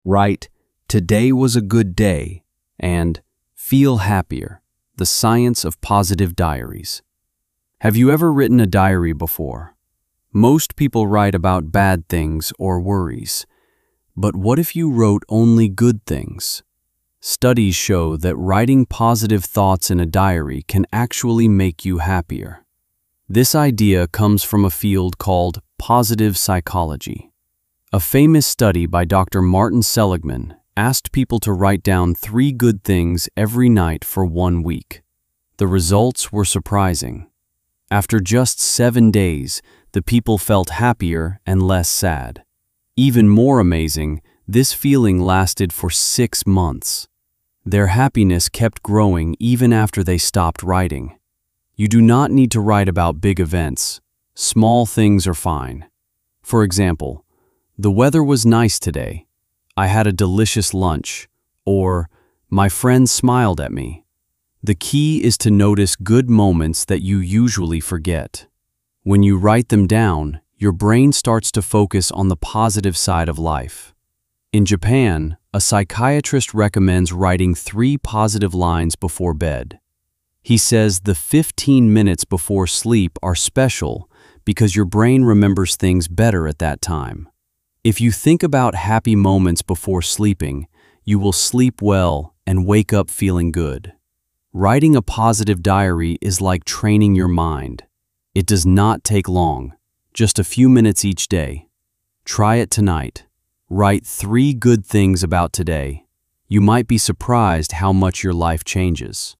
🔊 音読用音声